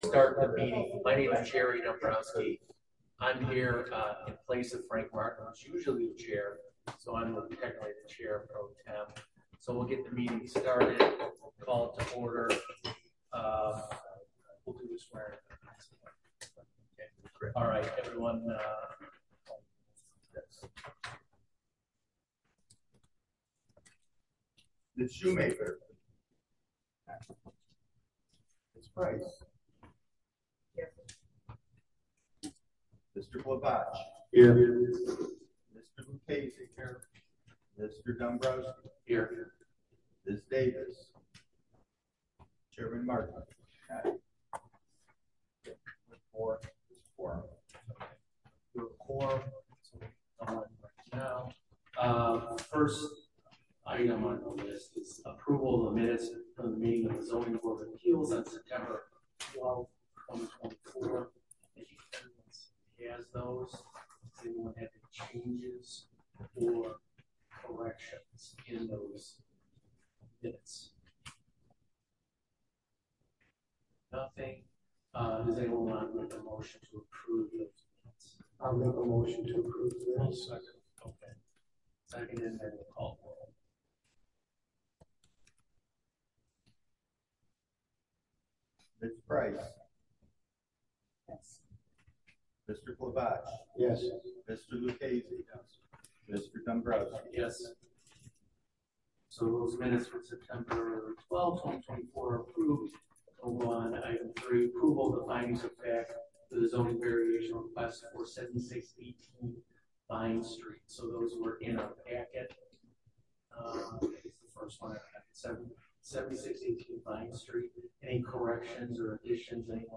Village Hall - 400 Park Avenue - River Forest - IL - 1st Floor - Community Room
Zoning Board of Appeals October 17, 2024, 7:30–9 pm Village Hall - 400 Park Avenue - River Forest - IL - 1st Floor - Community Room Add to calendar The Zoning Board of Appeals typically meets the 2nd Thursday of each month at 7:30 pm in the Community Room of Village Hall, as necessary.